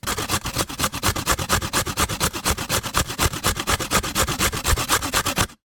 12. Морковь натирают на терке